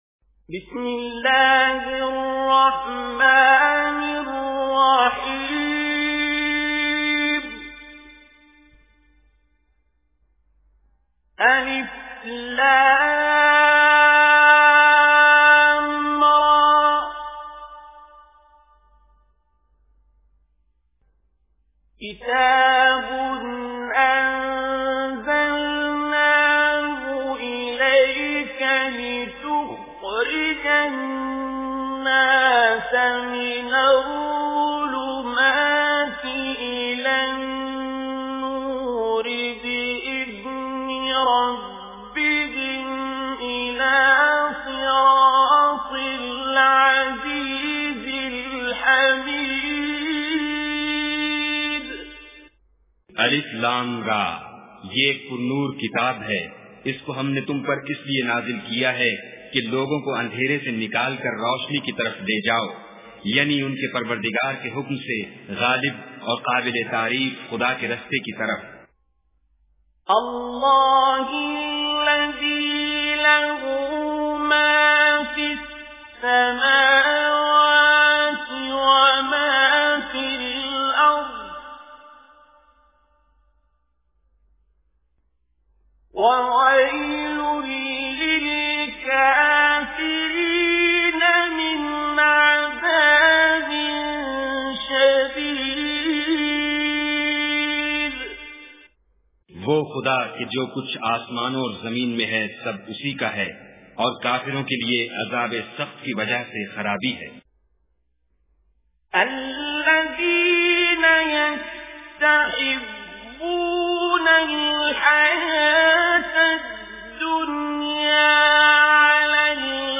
Listen online and download mp3 translation of Surah Ibrahim in Urdu. Listen tilawat in the voice of Qari Abdul Basit As Samad